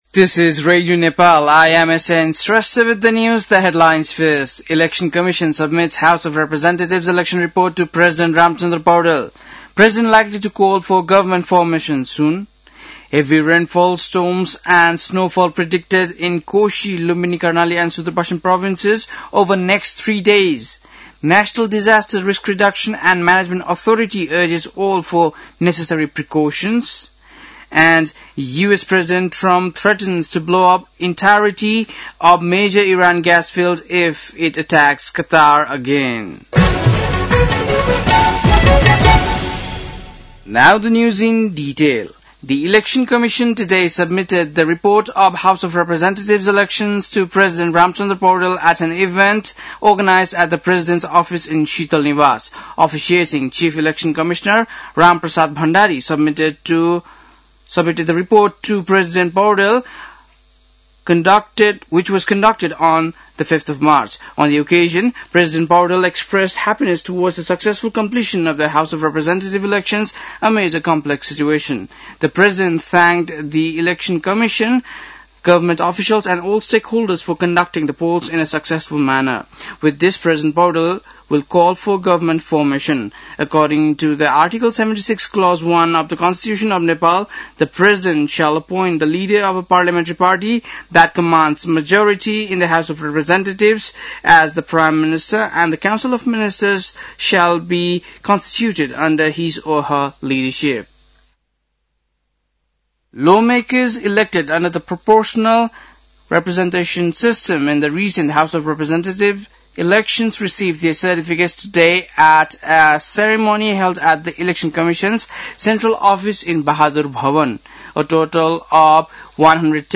बेलुकी ८ बजेको अङ्ग्रेजी समाचार : ५ चैत , २०८२
8-pm-english-news-12-05.mp3